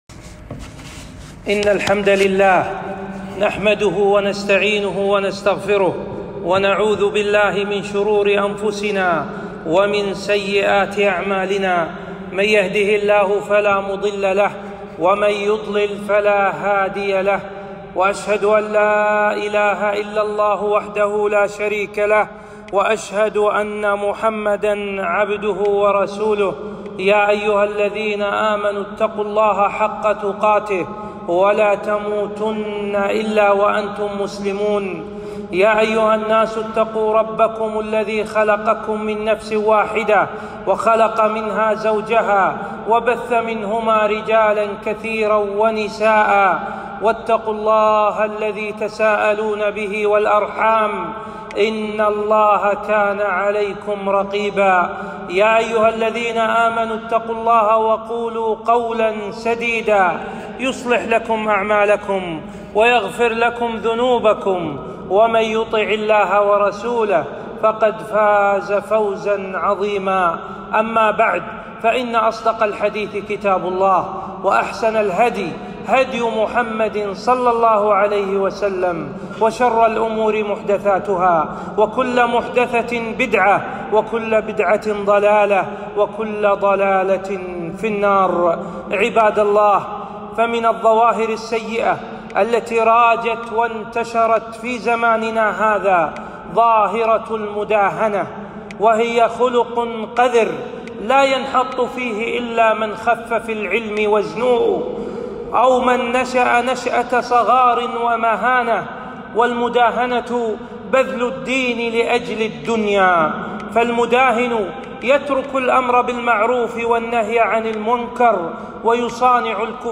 خطبة - المداهنة في الدين